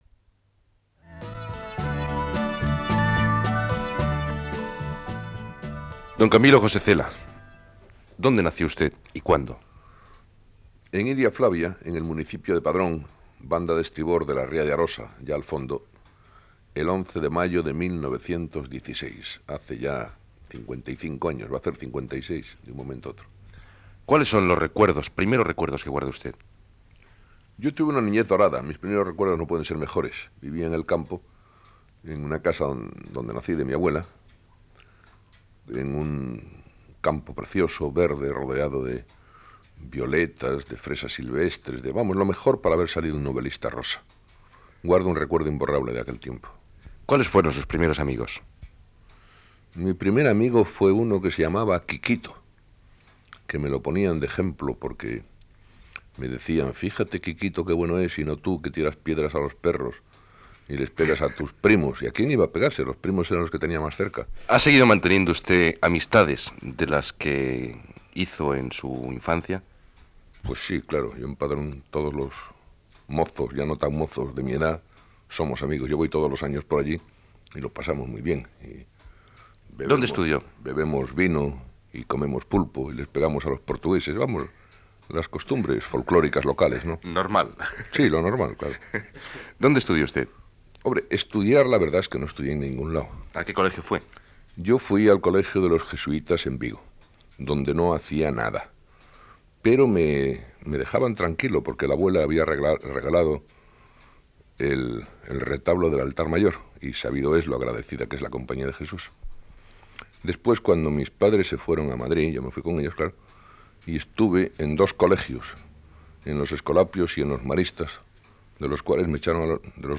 Entrevista a l'escriptor Camilo José Cela, qui parla de la seva infantesa i joventut